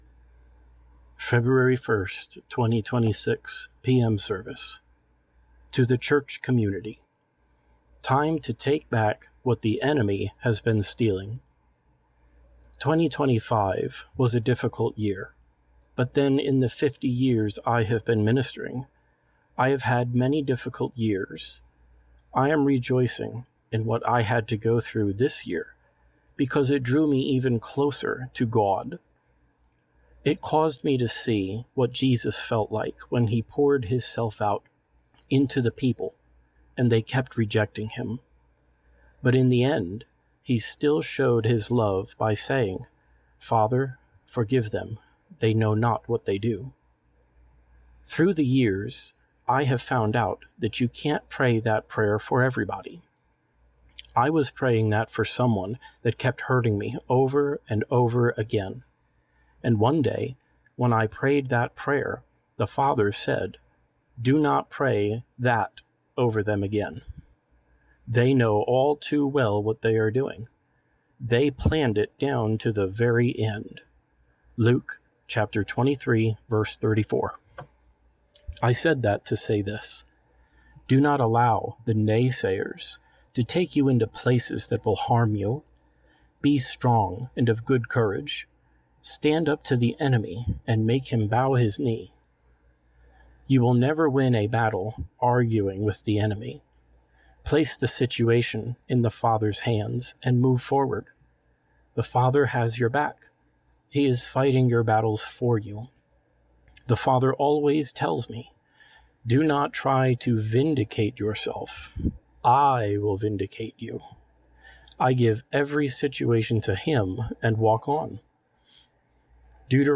letter-to-church_011.mp3